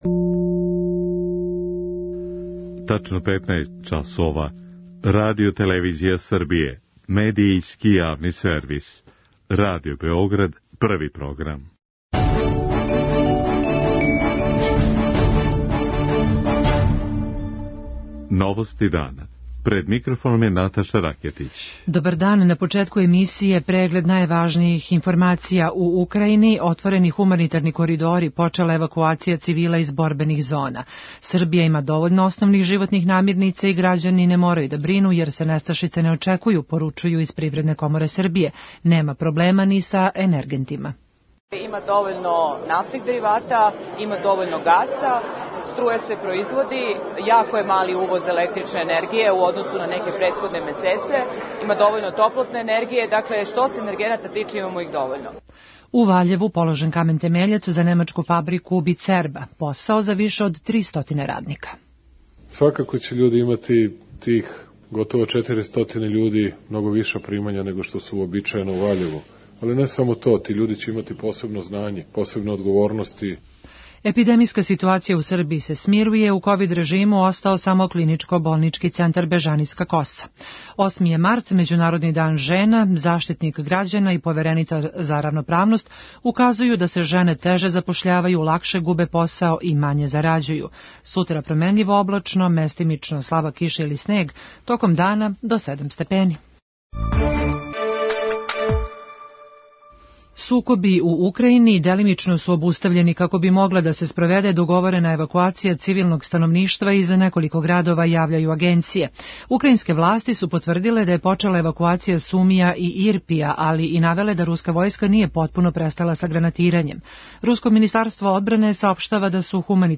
Европска комисија припрема нови пакет санкција Русији и Белорусији, сазнаје Ројтерс у Бриселу. преузми : 5.81 MB Новости дана Autor: Радио Београд 1 “Новости дана”, централна информативна емисија Првог програма Радио Београда емитује се од јесени 1958. године.